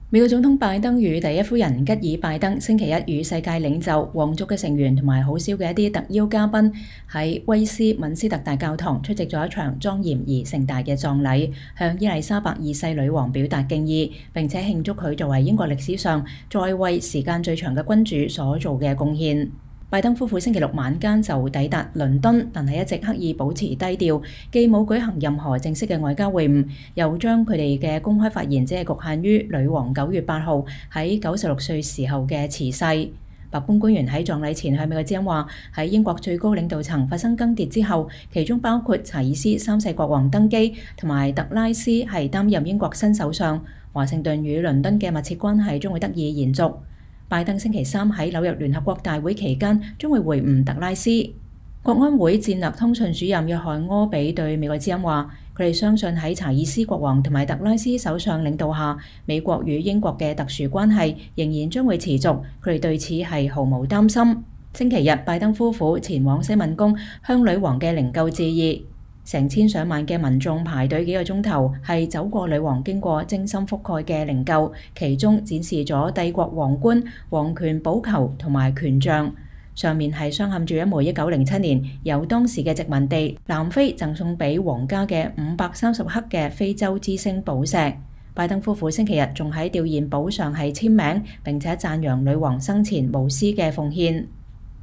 美國總統喬∙拜登與第一夫人吉爾∙拜登（Jill Biden）星期一（9月19日）與世界領袖、皇族成員以及很少的一些特邀嘉賓在倫敦威斯敏斯特大教堂出席了一場莊嚴而盛大葬禮，向伊麗莎白二世女王表達敬意，並慶祝她作為英國歷史上在位時間最長的君主所做的貢獻。